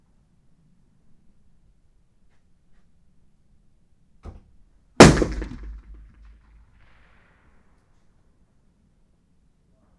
Environmental
Streetsounds
Noisepollution
UrbanSoundsNew / 01_gunshot /shot556_51_ch01_180718_162421_43_.wav